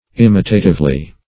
imitatively - definition of imitatively - synonyms, pronunciation, spelling from Free Dictionary
-- Im"i*ta*tive*ly, adv.